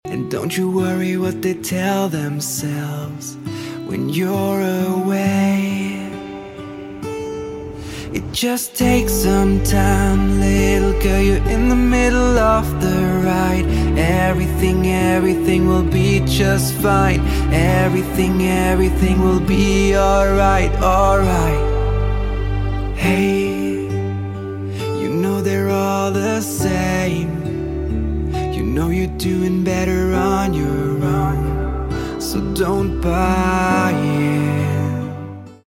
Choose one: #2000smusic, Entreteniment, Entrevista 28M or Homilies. #2000smusic